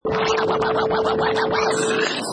Sound bytes: DJ Scratch 105 1
Professional killer vinyl scratch perfect for sampling, mixing, music production, timed to 105 beats per minute
Product Info: 48k 24bit Stereo
Category: Musical Instruments / Turntables
Try preview above (pink tone added for copyright).
DJ_Scratch_105_1.mp3